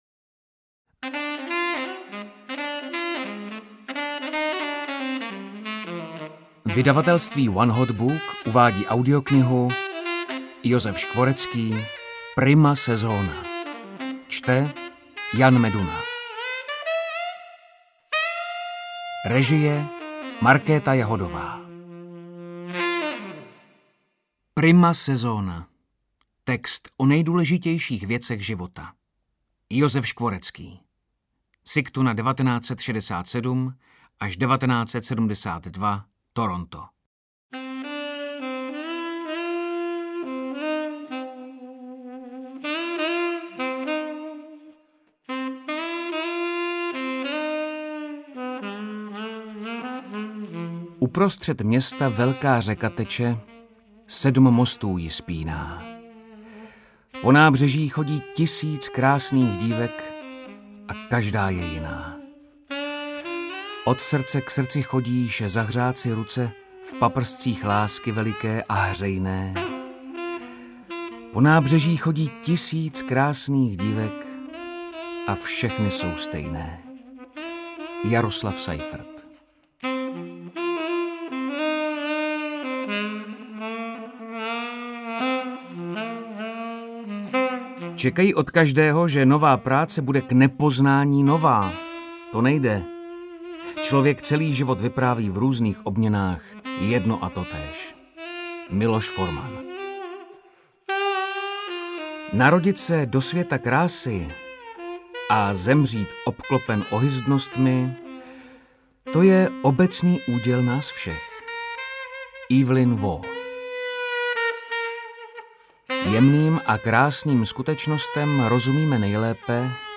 Interpret:  Jan Meduna